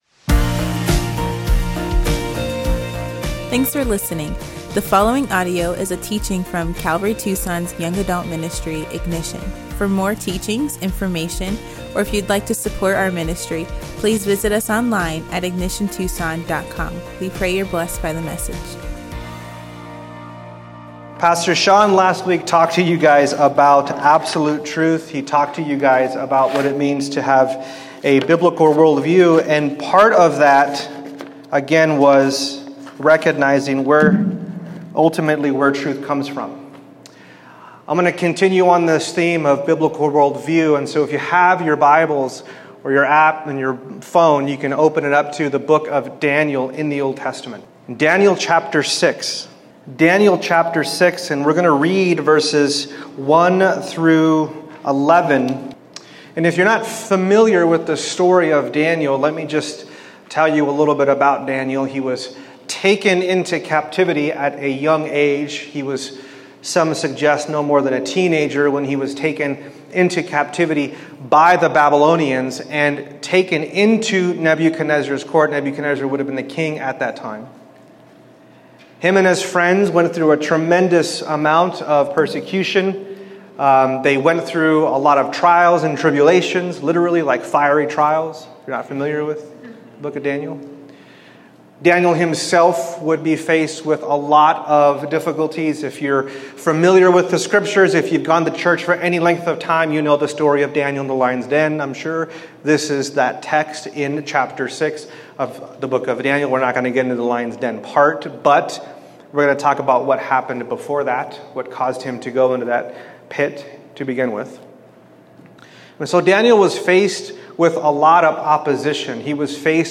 teaches on the importance of prayer in forming your worldview